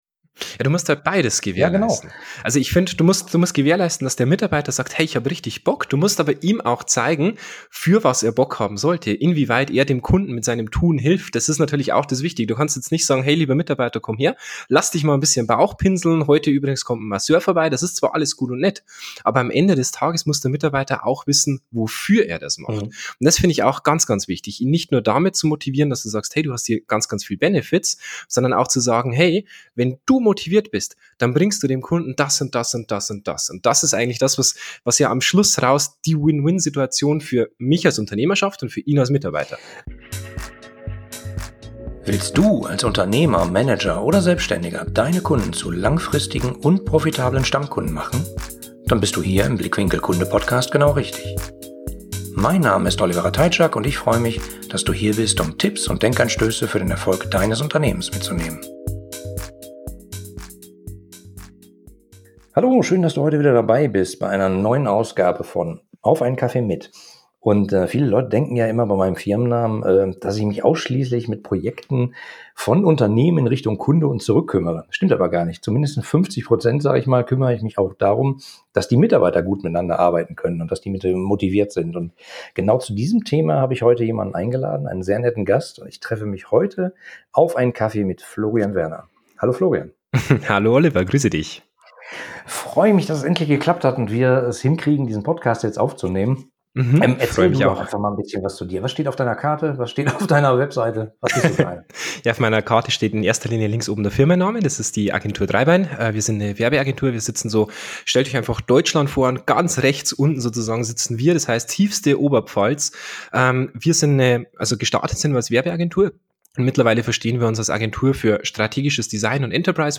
Im lockeren Gespräch über moderne Wege der Mitarbeitermotivation jenseits von Gehaltserhöhungen!